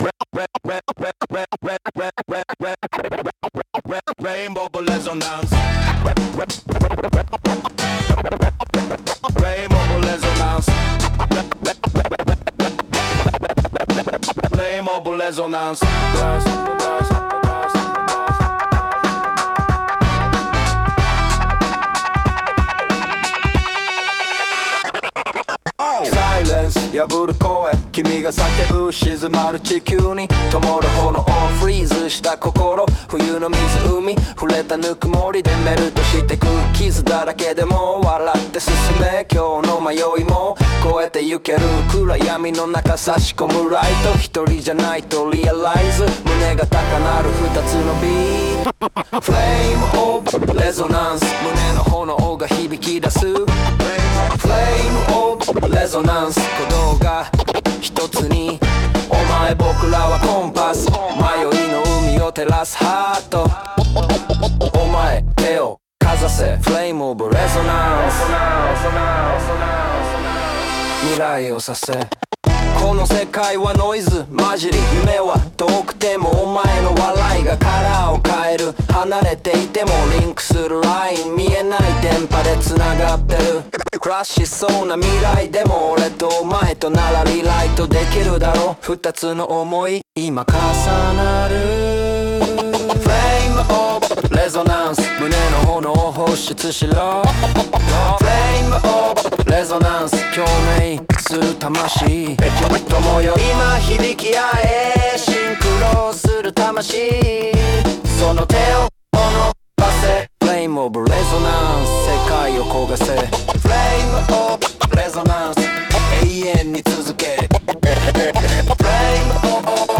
男性ボーカル
テクノ
イメージ：テクノ,男性ボーカル,スクラッチ